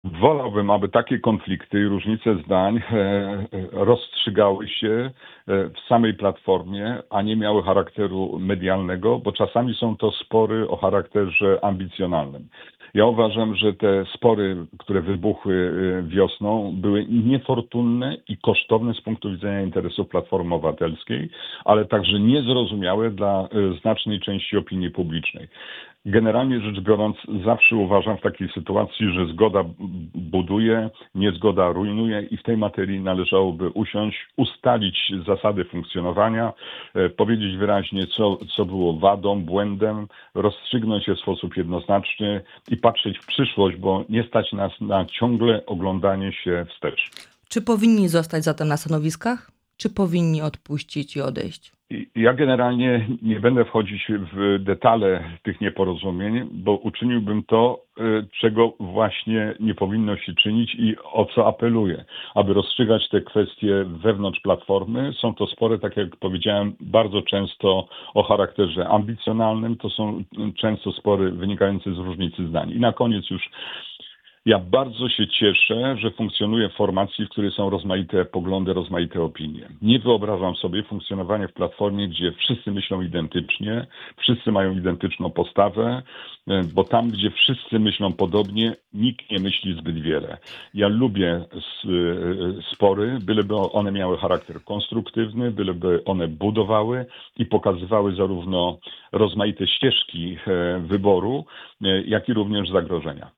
W rozmowie „Poranny Gość” z europosłem Bogdanem Zdrojewskim rozmawialiśmy o wizycie prezydenta Karola Nawrockiego w USA, relacjach z rządem, a także o zmianach i wyborach w PO.